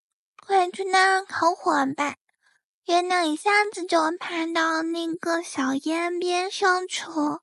正常#zh#快去那烤火吧,月亮一下子就爬到那个小烟边上去了.wav